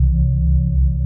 SPACECRAFT_Hover_03_loop_mono.wav